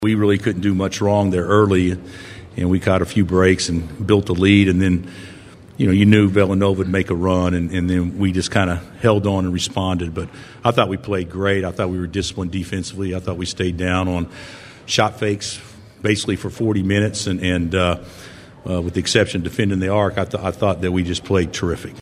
Kansas Coach Bill Self said they came ready to play.